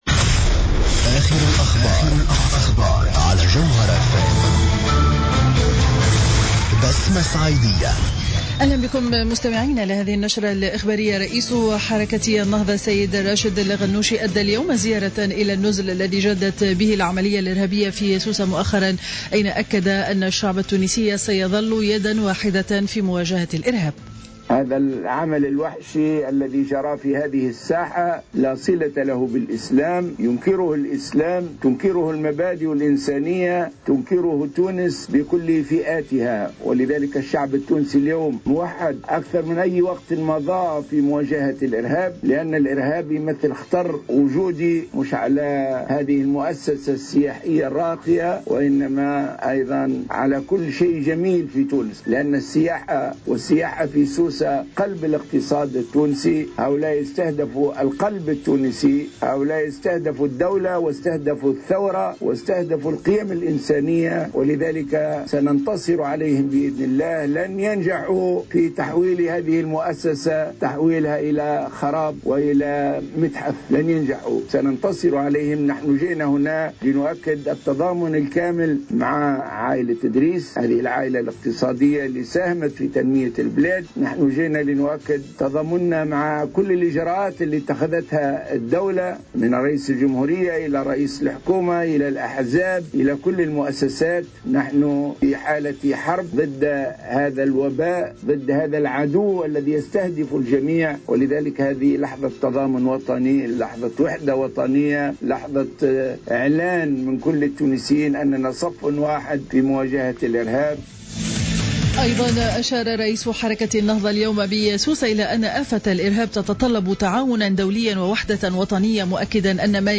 نشرة أخبار منتصف النهار ليوم الخميس 09 جويلية 2015